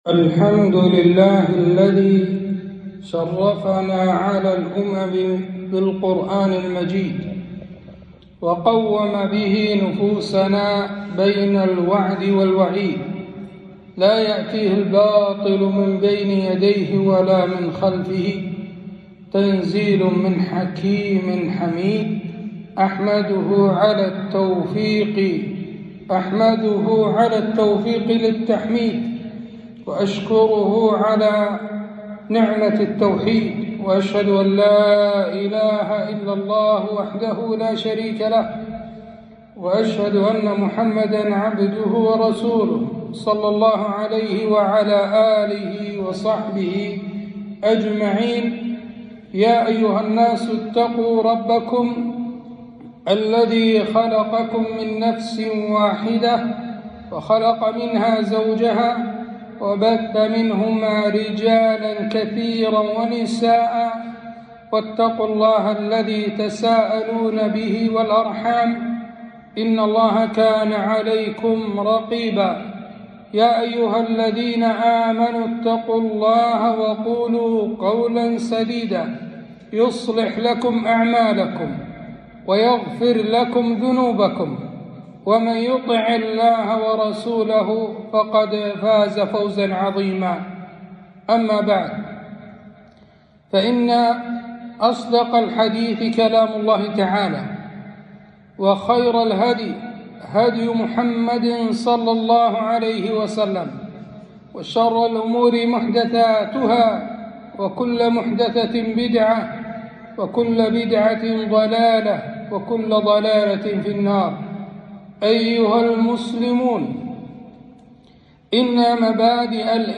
خطبة - صلة الرحم